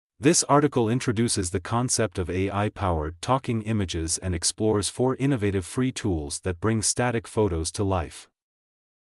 mp3-output-ttsfreedotcom-1.mp3